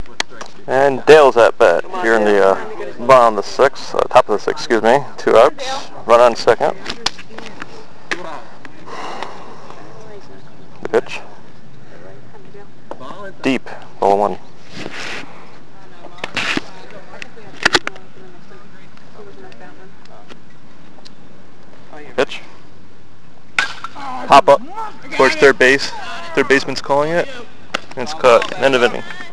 Play-by-play coverage